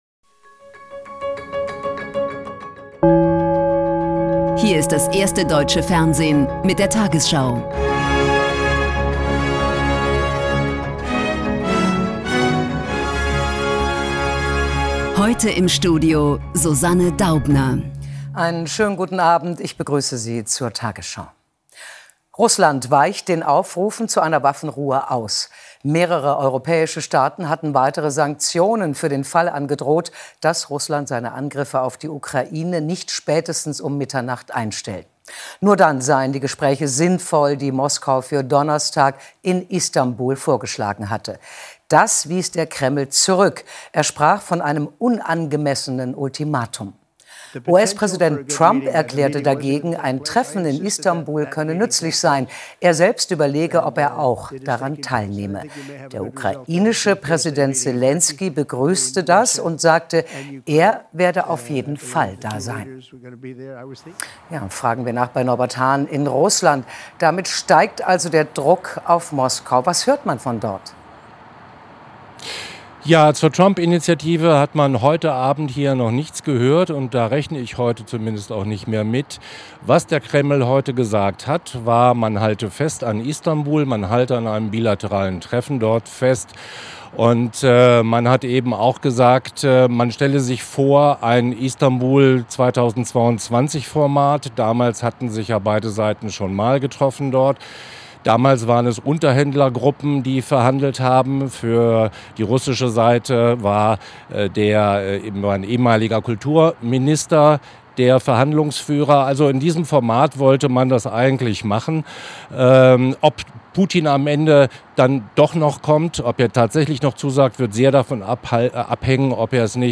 Die 20 Uhr Nachrichten von heute zum Nachhören. Hier findet ihr immer, was am Tag aktuell und wichtig ist in den News.